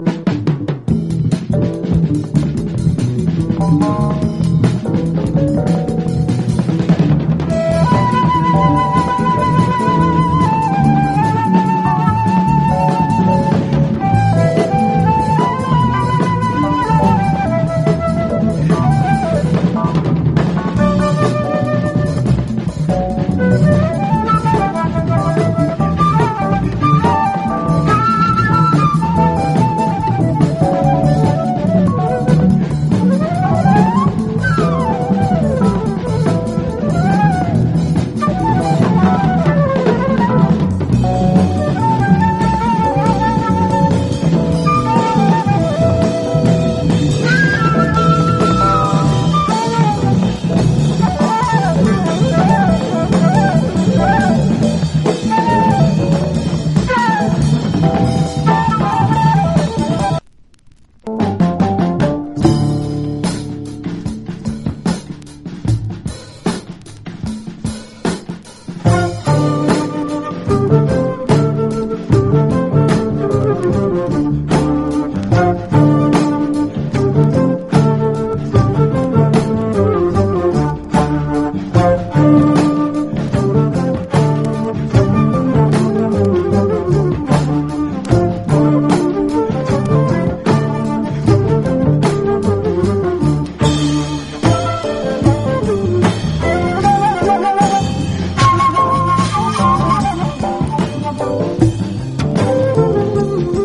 SOUL / SOUL / 60'S / NORTHERN SOUL / CHICAGO SOUL